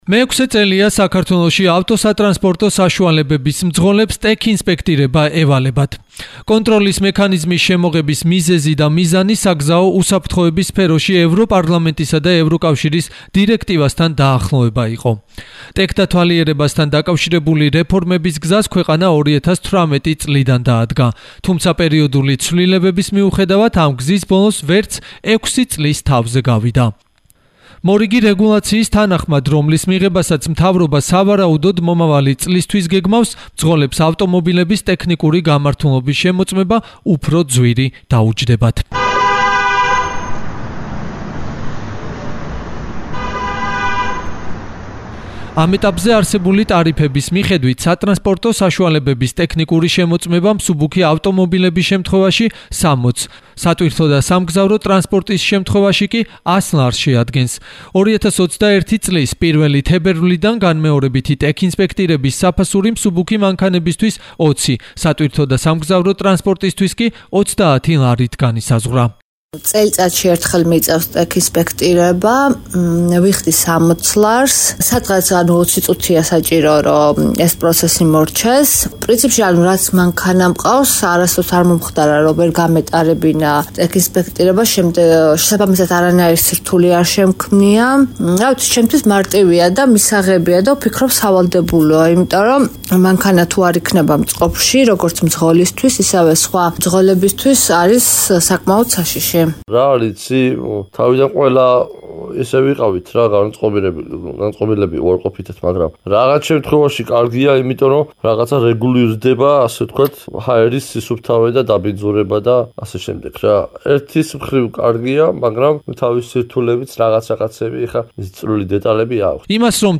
რადიო "თბილისის" საგამოძიებო-საზოგადოებრივი პროექტი "სატრანსპორტო ლაბირინთი"